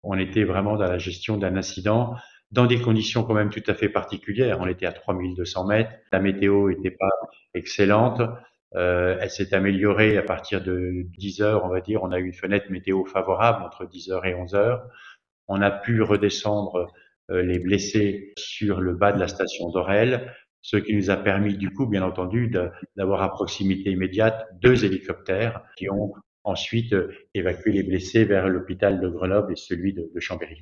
François Ravier, le préfet de Savoie revient sur les opérations de secours qui ont mobilisé plus de 120 personnes ce matin à Val Thorens.